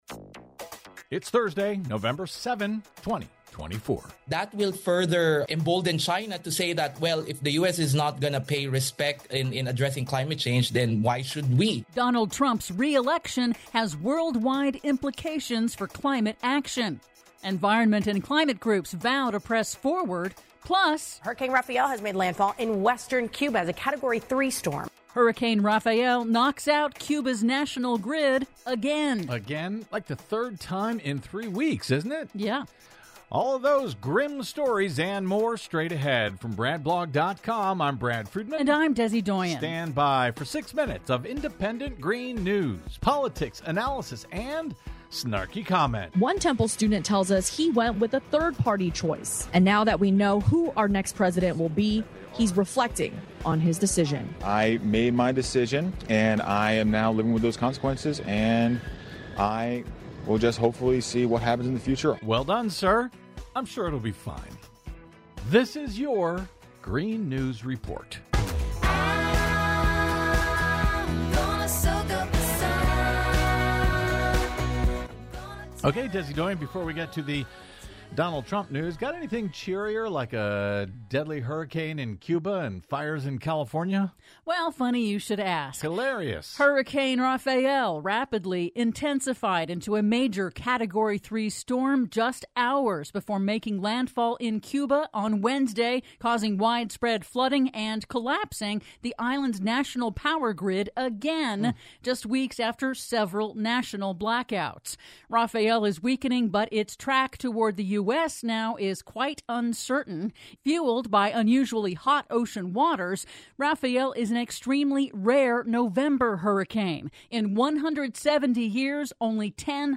IN TODAY'S RADIO REPORT: Donald Trump's re-election has worldwide implications for climate action; Environment and climate groups vow to press forward; PLUS: Hurricane Rafael knocks out Cuba's national electric grid, again... All that and more in today's Green News Report!